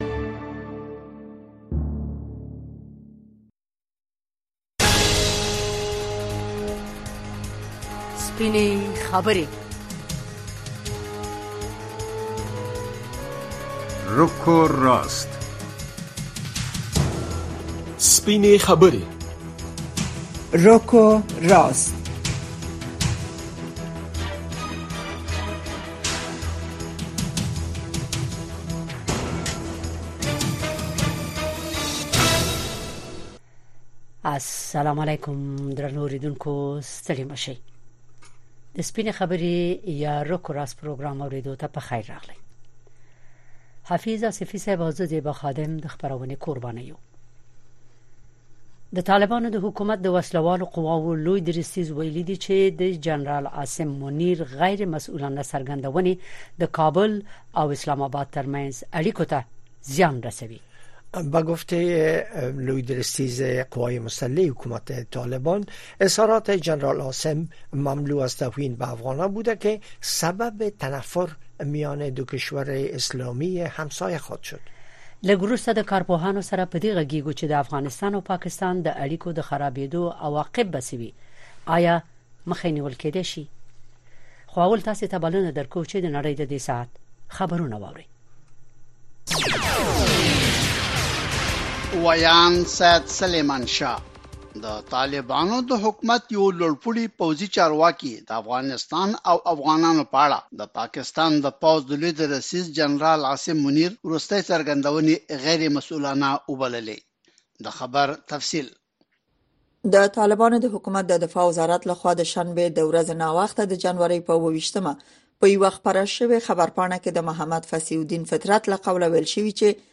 در برنامۀ رک و راست بحث‌های داغ صاحب‌نظران و تحلیلگران را در مورد رویدادهای داغ روز در افغانستان دنبال کرده می‌توانید. این برنامه زنده به گونۀ مشترک به زبان‌های دری و پشتو هر شب از ساعت هشت تا نه شب به وقت افغانستان پخش می‌شود.